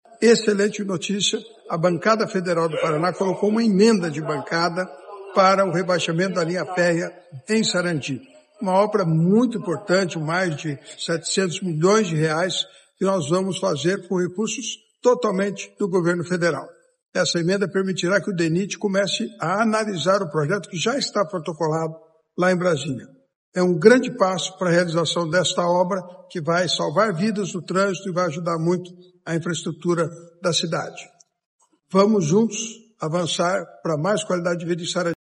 Ouça o que diz o deputado: